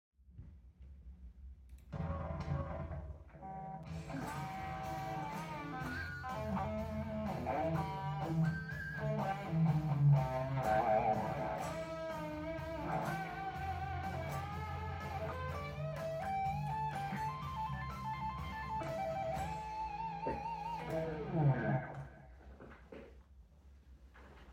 A-solo-I-wrote-1.mp3